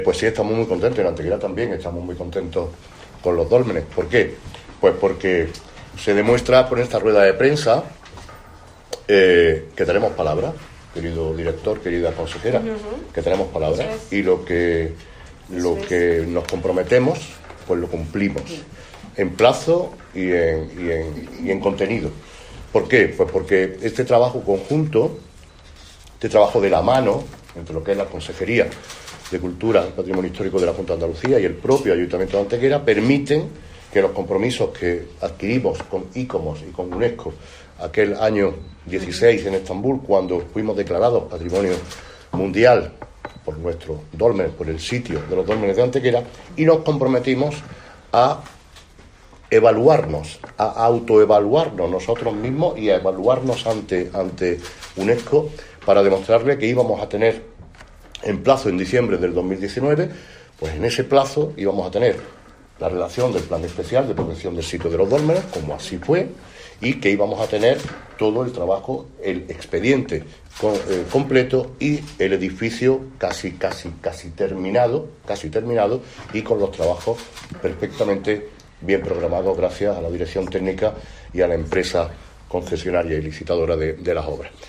Valoración del Alcalde de Antequera
Cortes de voz